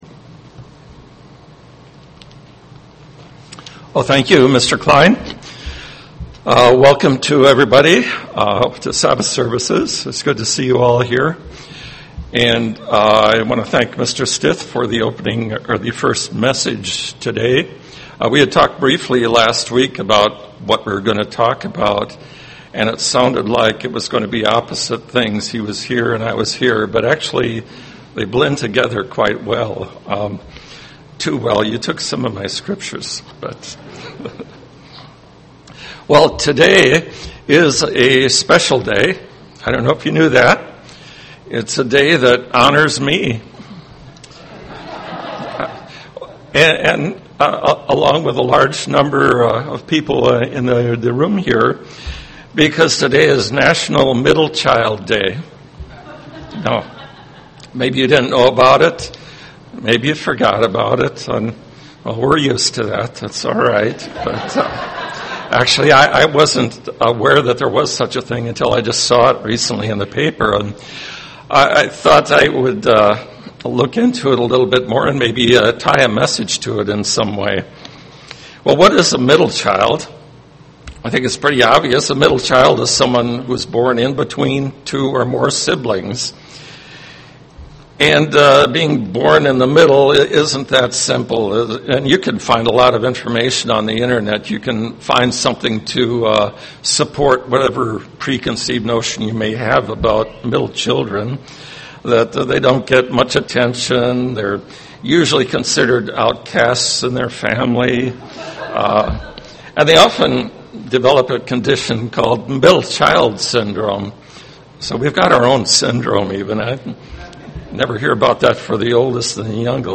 Given in Twin Cities, MN
UCG Sermon confidence self-esteem Studying the bible?